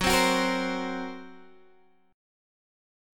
GbMb5 Chord
Listen to GbMb5 strummed